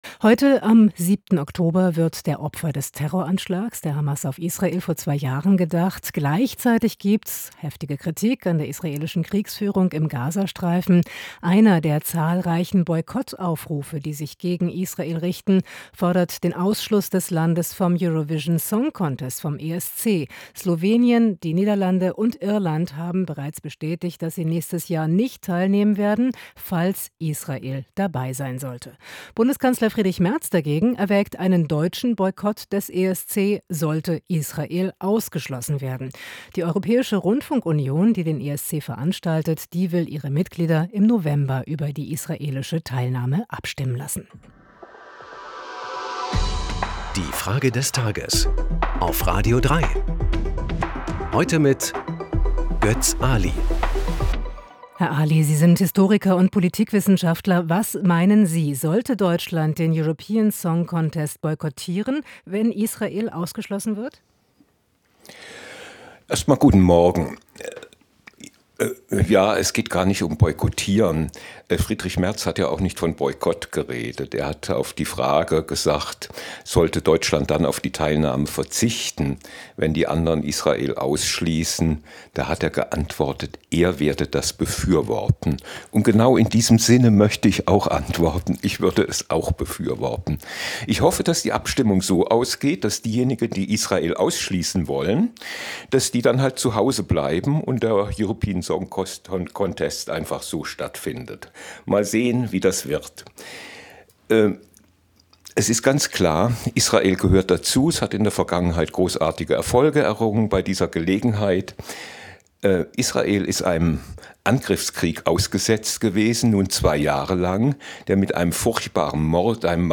Politikwissenchaftler Götz Aly.